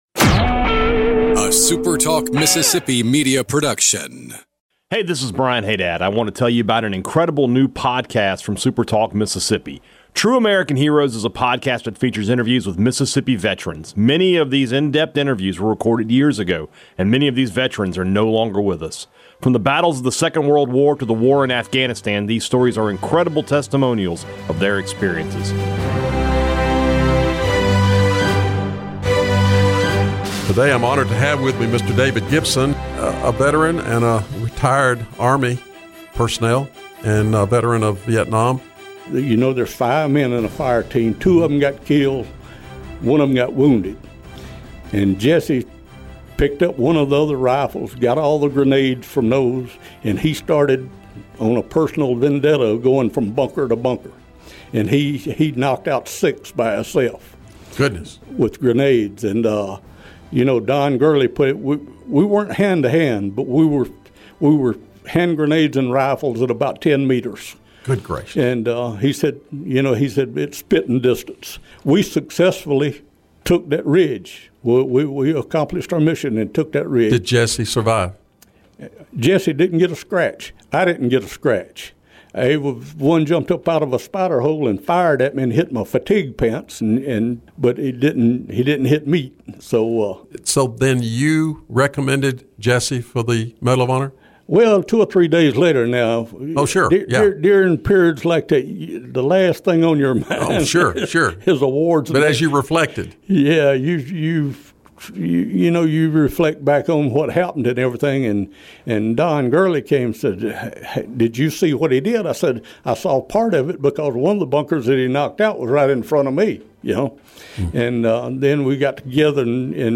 True American Heroes from SuperTalk Mississippi is a podcast that features interviews with Mississippi veterans. Many of these in-depth interviews were recorded years ago and many of these veterans are no longer with us.